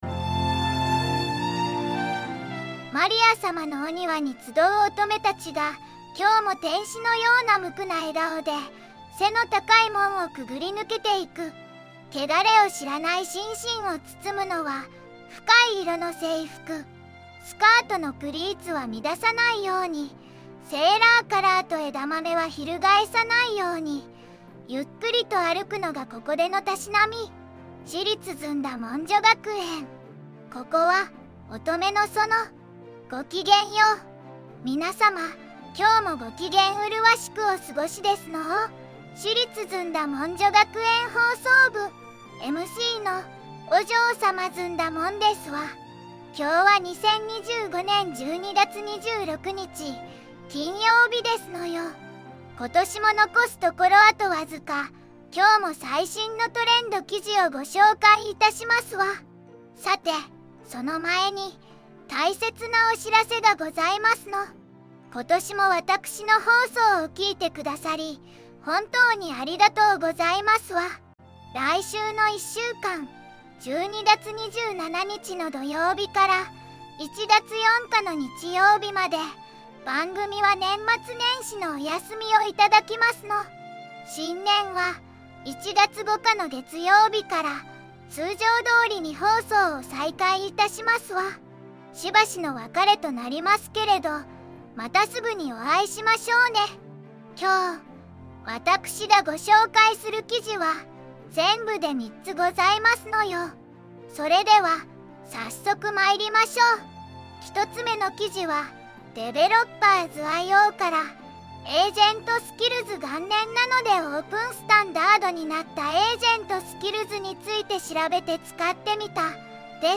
お嬢様ずんだもん
VOICEVOX:ずんだもん